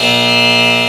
scanbuzz.ogg